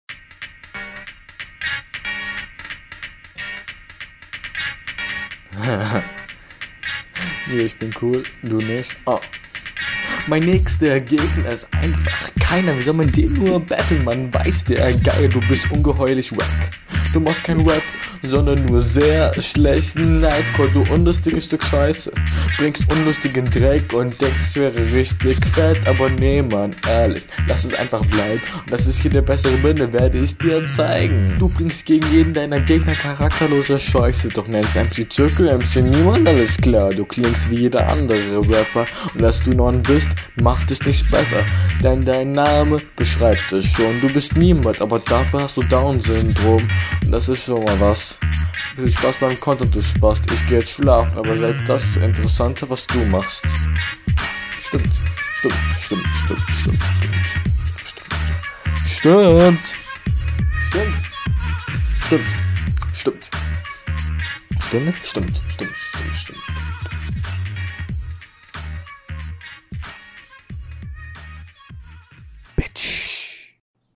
Joa klingt halt noch sehr nach Anfänger, ist halt nicht wirklich gerappt, sondern geredet.
Sehr cooler Beat, Veteranen kennen noch.